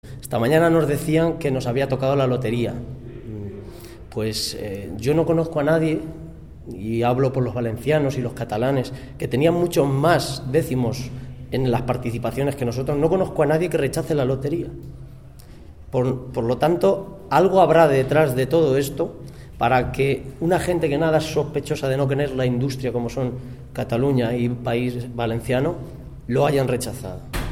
Cortes de audio de la rueda de prensa
Audio alcalde Almonacid Marquesado
alcalde_Almonacid_del_Marquesado.mp3